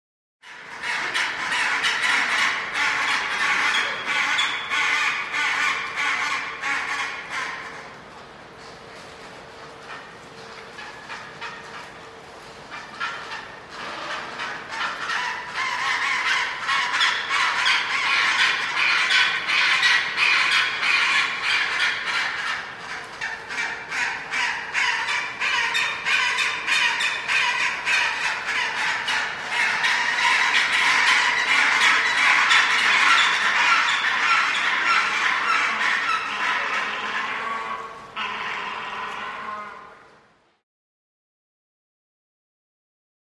animal
Large Penguin Colony Indoor Ambience